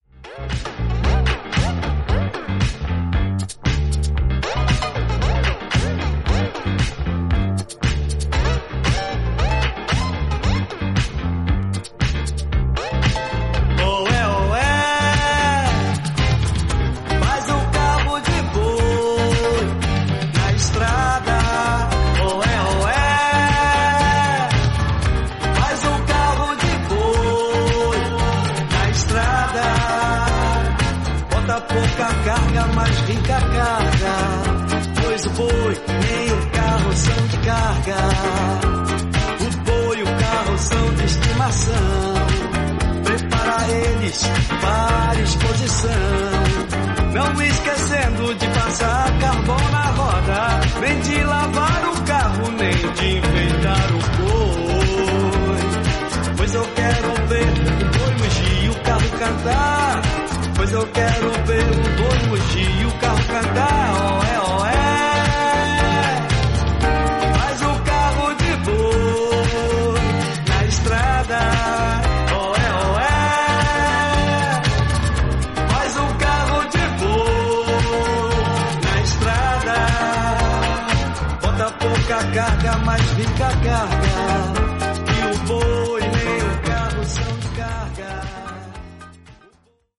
バレアリックな音源に焦点を当てた人気シリーズ